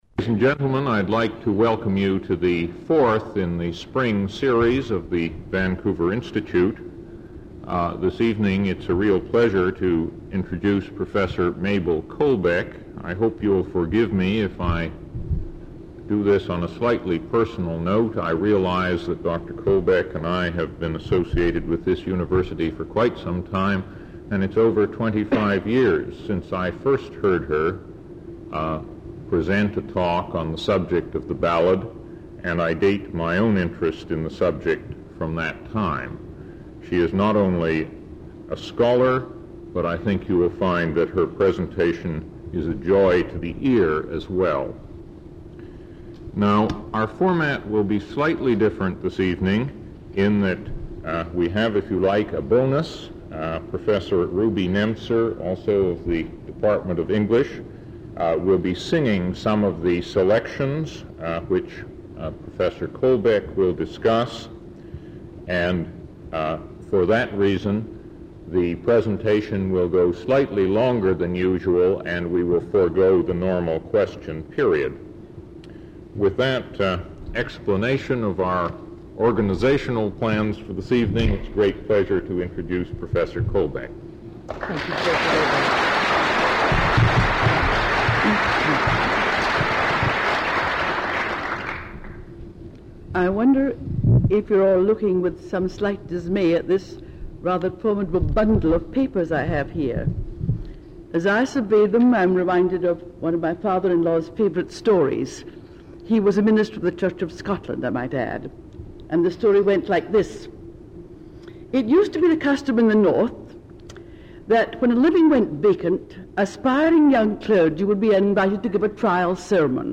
Original audio recording available in the University Archives (UBC VT 461).